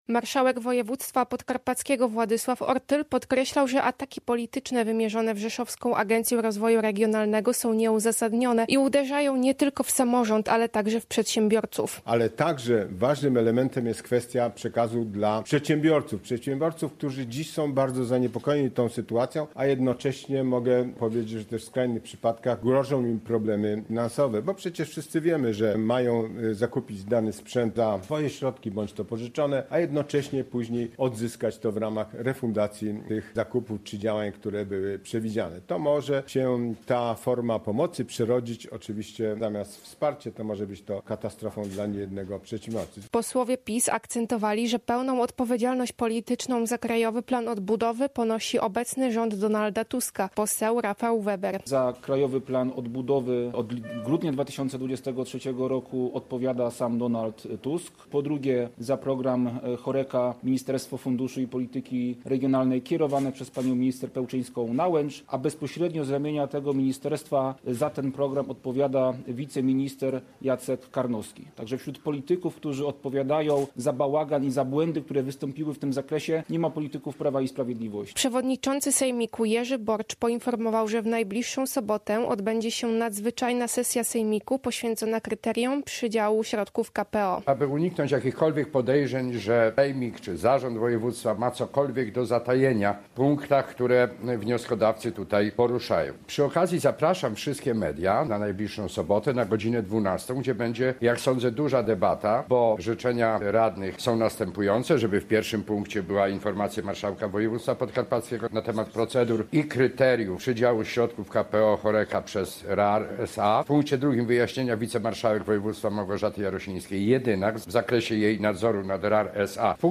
Poseł Rafał Weber mówił, że wątpliwości budzi fakt, iż dofinansowania trafiają do rodzin polityków Koalicji Obywatelskiej.